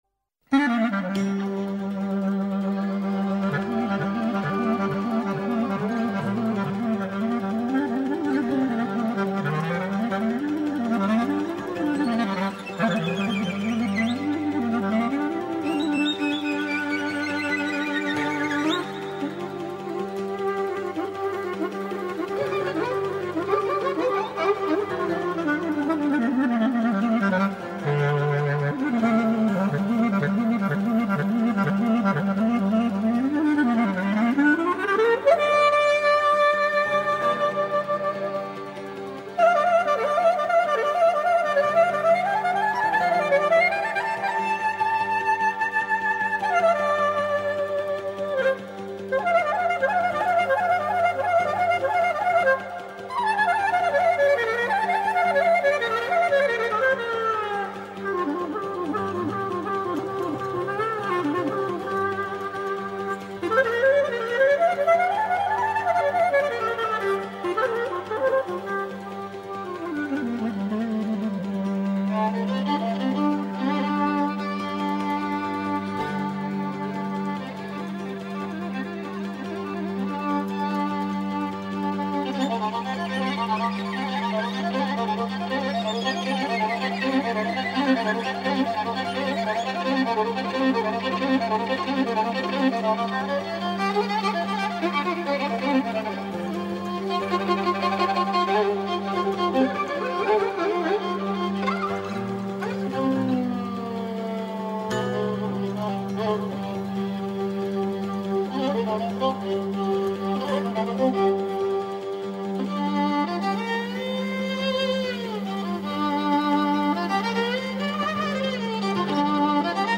αφηγείται παραμύθια